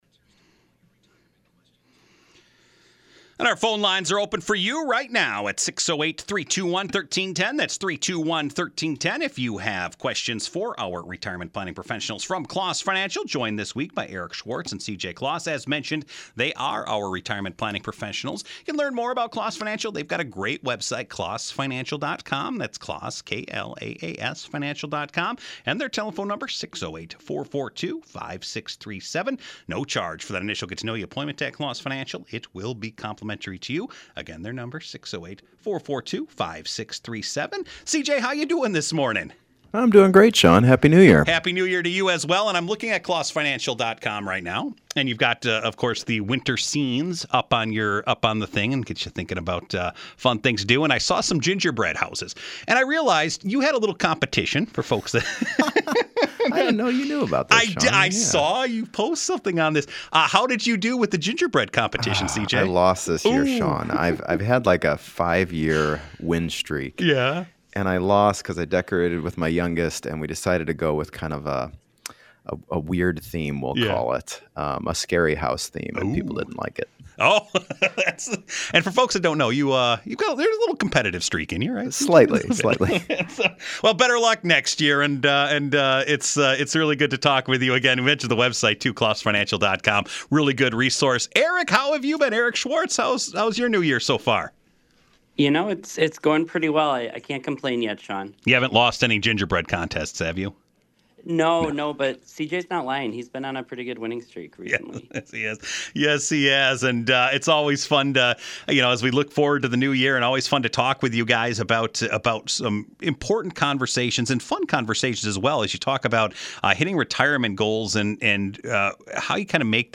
Answers to all your retirement questions in one place. Once a week we take calls and talk about getting your financial house in order.
Shows are broadcast live every Thursday from 8:05-8:35 am on “Madison in the Morning” on News/Talk 1310 WIBA AM in Madison, Wisconsin.